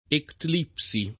ectlipsi [ ektl & p S i ] → ettlissi